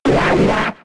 Cri d'Akwakwak K.O. dans Pokémon X et Y.